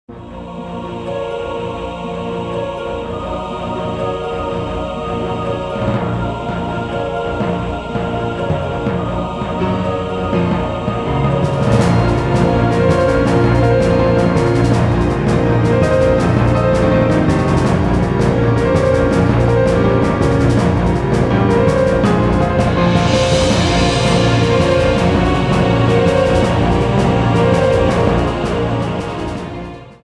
Category:Boss themes